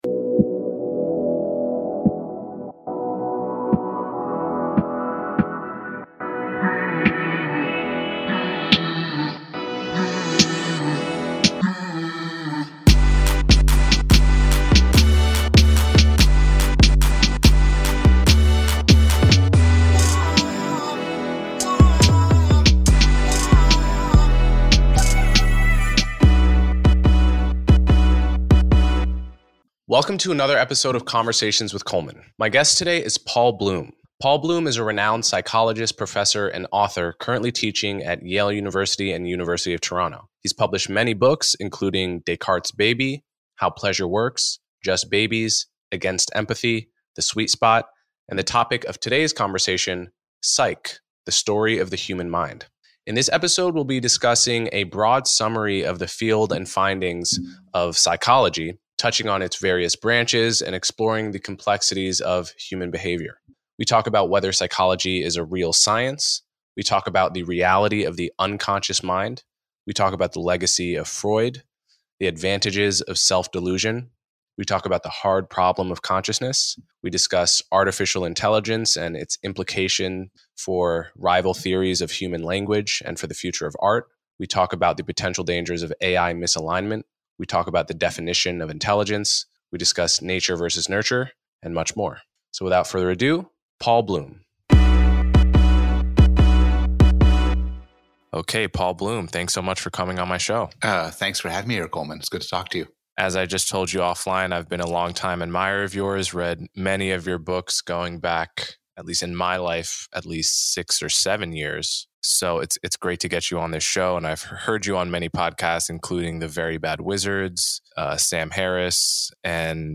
My guest today is Paul Bloom.